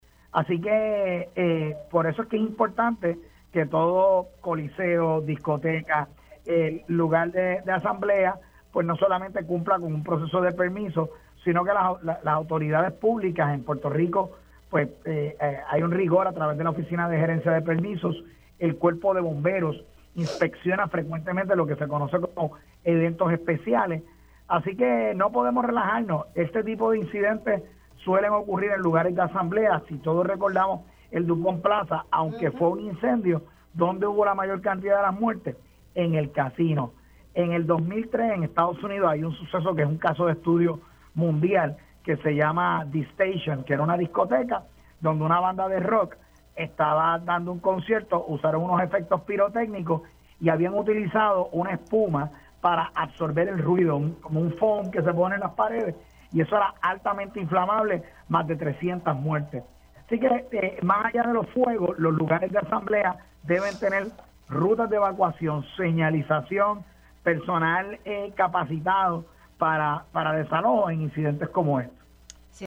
El exjefe del Cuerpo de Bomberos de Puerto Rico, Ángel Crespo señaló en Pega’os en la Mañana que el colapso del techo en la discoteca Jet Set en Santo Domingo, República Dominicana no es el primer incidente en el local.